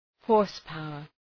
Προφορά
{‘hɔ:rs,paʋər}